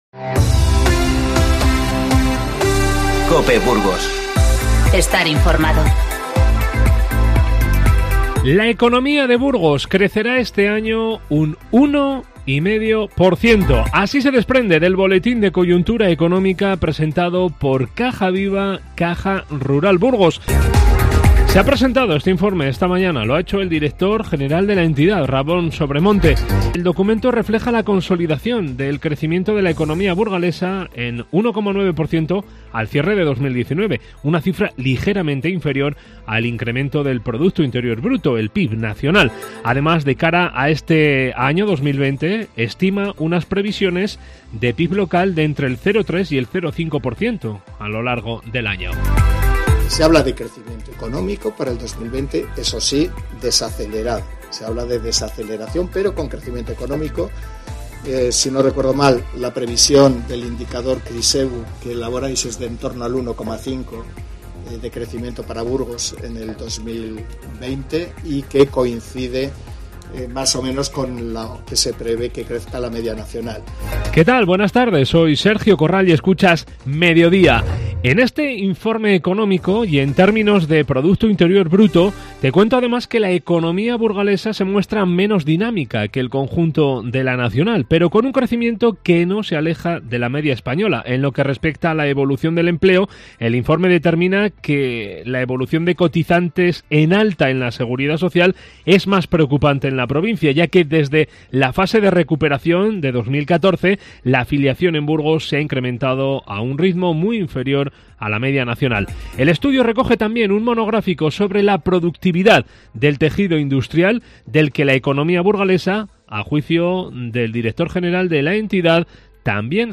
Informativo 16-01-20